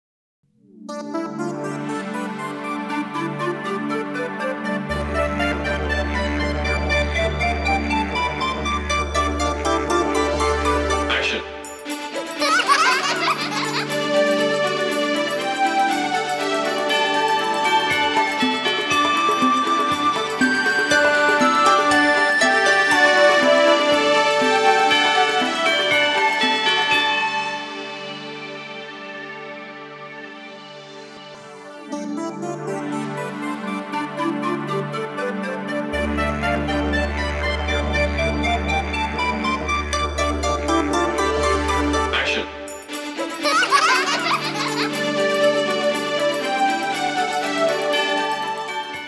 Musique d’attente pour le standard téléphonique de l’entreprise Lafarge-pôle technologique de L’Isle d’Abeau (38).